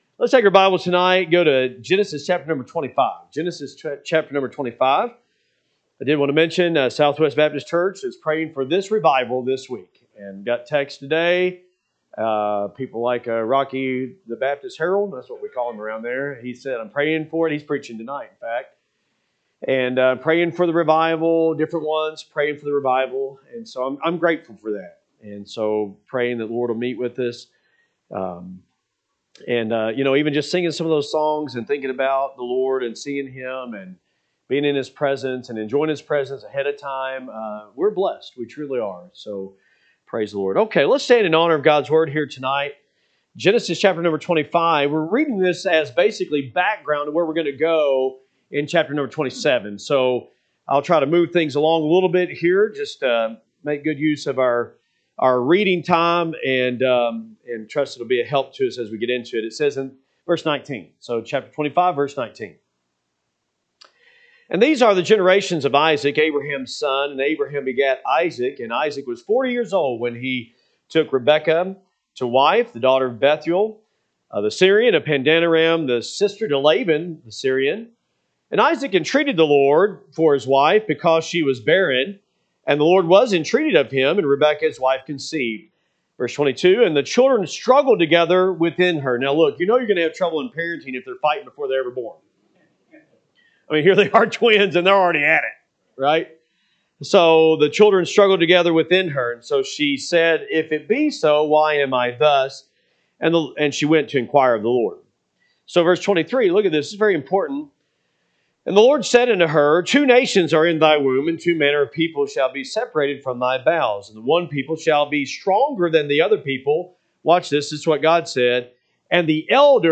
April 12, 2026 pm Service Genesis 25:19-28 (KJB) 19 And these are the generations of Isaac, Abraham’s son: Abraham begat Isaac: 20 And Isaac was forty years old when he took Rebekah…